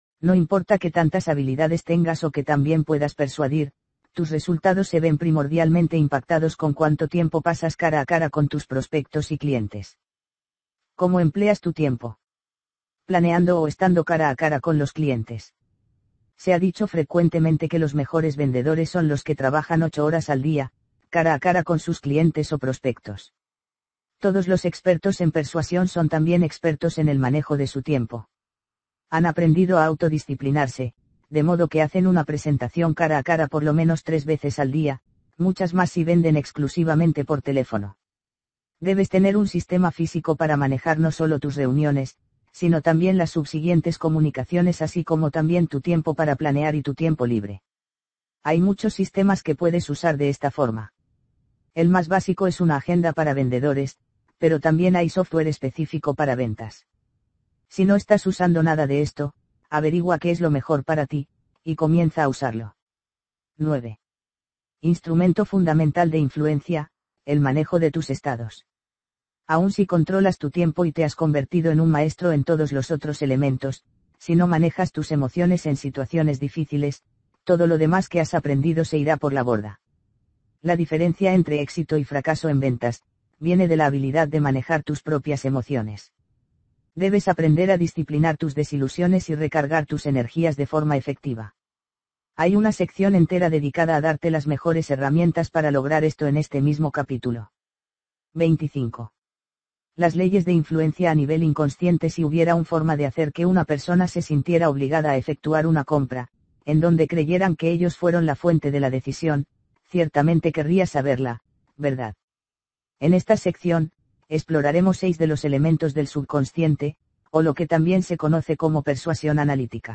Audiolibro-El-vendedor-elegantemente-irresistible-Parte-2.mp3